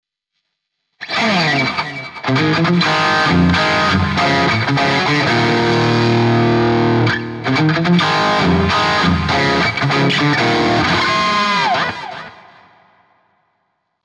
essai de son à la VH